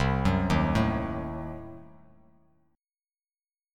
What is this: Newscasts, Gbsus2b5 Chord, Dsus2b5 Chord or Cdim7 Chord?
Cdim7 Chord